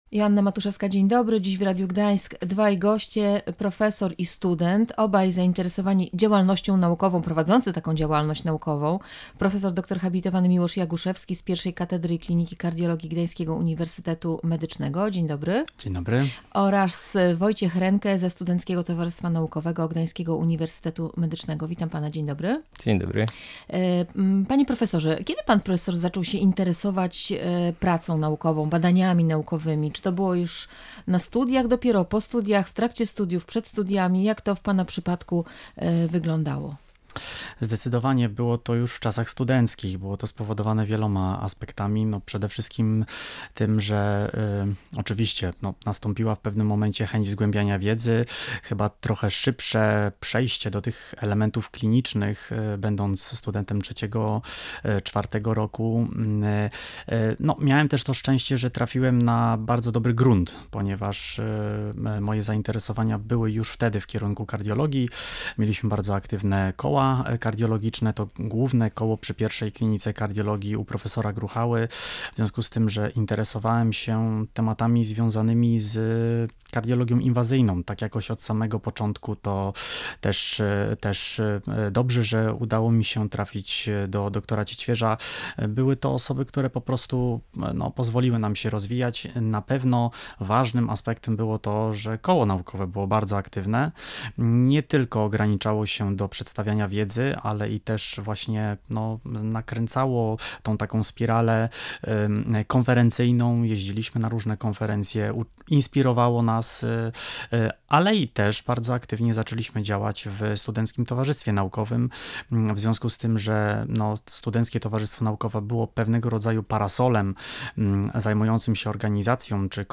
Skąd bierze się zainteresowanie pracą naukową? Jak rozwijać się w tym kierunku jeszcze na studiach? Czym są koła i towarzystwa naukowe oraz w czym mogą pomóc? Między innymi o tym rozmawialiśmy w audycji „Radiowo-Naukowo”.